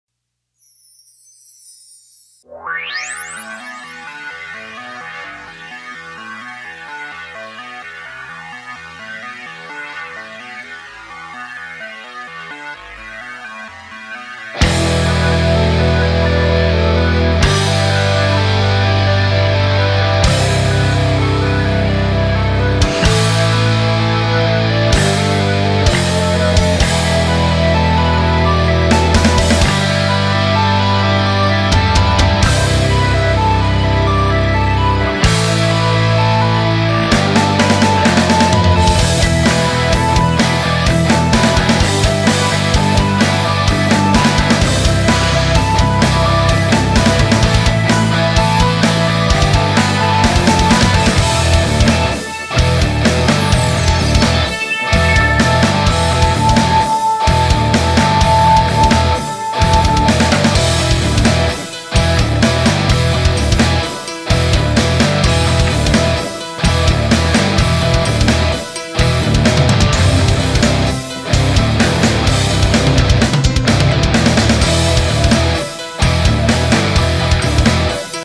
I'm liking this tone a lot!
That mini recto sounds very good!